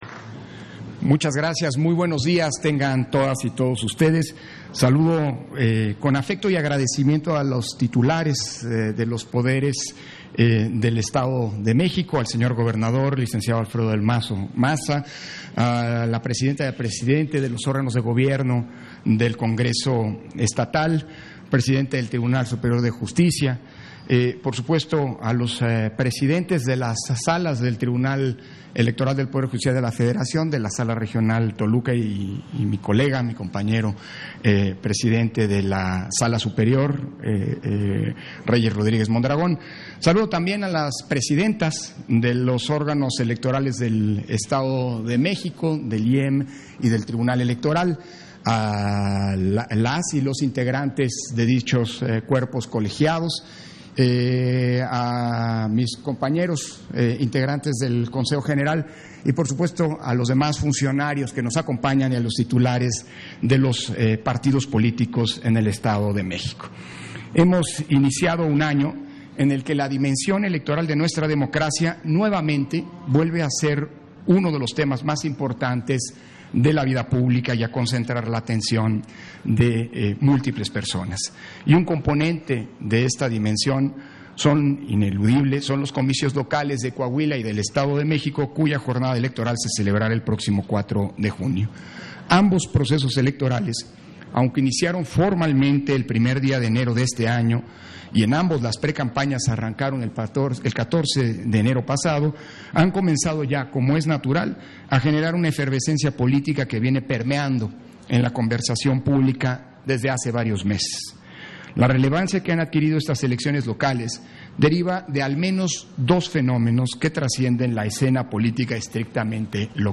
190123_AUDIO_INTERVENCIÓN-CONSEJERO-PDTE.-CÓRDOVA-FIRMA-DEL-ACUERDO-POR-LA-INTEGRIDAD-ELECTORAL - Central Electoral